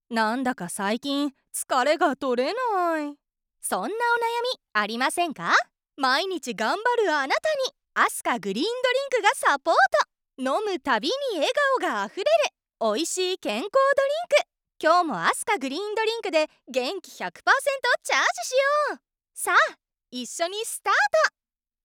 元CATVアナウンサーが温かみのある爽やかな声をお届けします。
通販、CM風、元気な